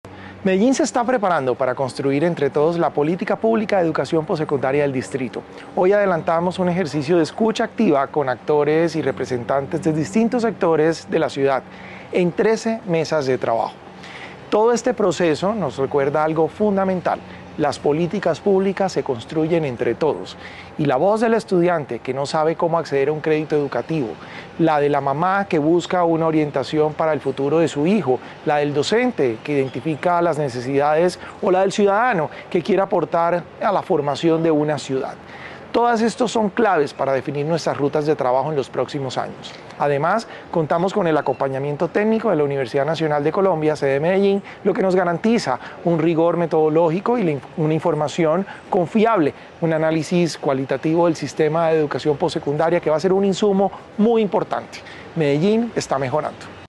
Declaraciones director general de Sapiencia, Salomón Cruz Zirene
Declaraciones-director-general-de-Sapiencia-Salomon-Cruz-Zirene.mp3